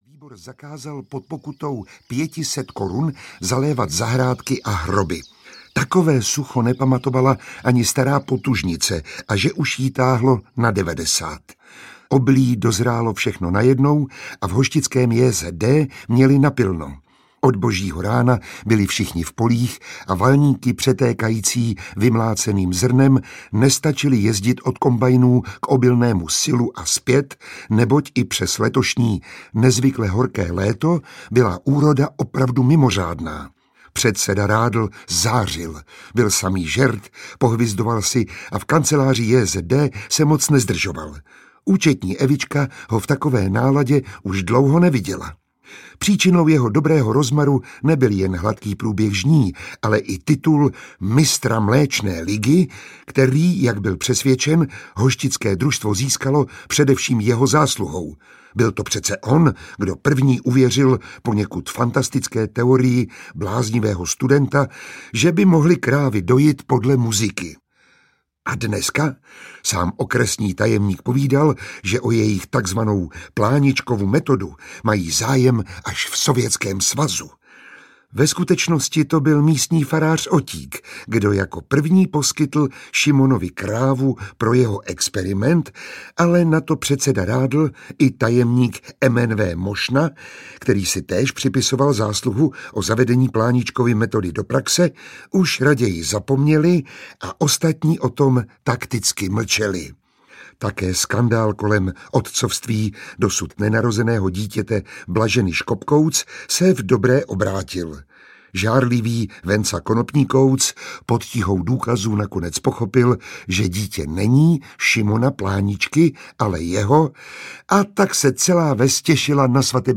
Čte Miroslav Táborský, režie Zdeněk Troška.
Ukázka z knihy
Režisér Zdeněk Troška v nahrávacím studiu připravil „film pro uši“. Četbu herce Miroslava Táborského doplňuje řada zvukových efektů a známých hudebních motivů, takže posluchač si celý příběh skvěle vychutná.